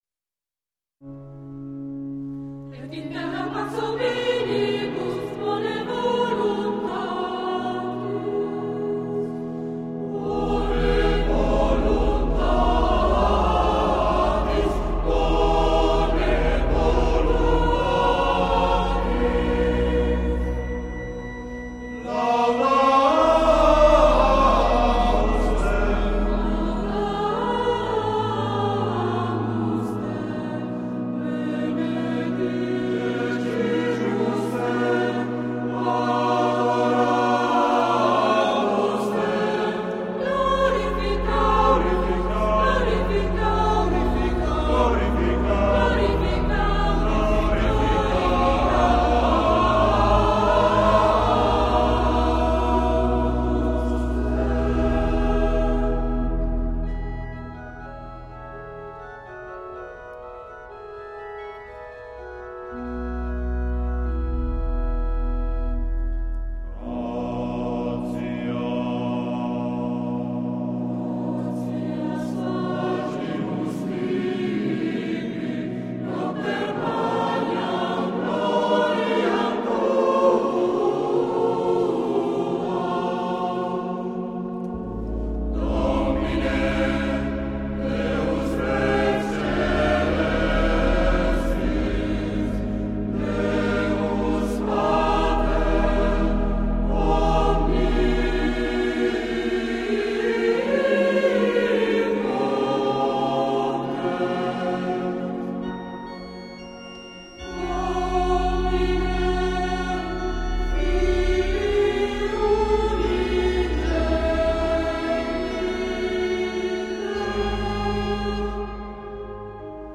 In questa sezione è possibile "scaricare" sul proprio computer alcuni esempi di brani eseguiti dalla Corale Polifonica Nazariana.